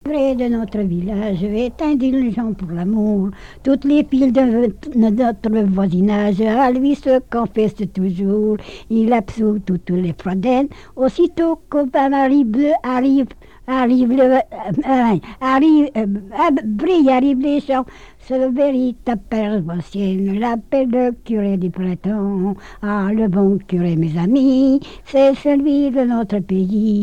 Genre strophique
collecte en Vendée
répertoire de chansons, et d'airs à danser
Pièce musicale inédite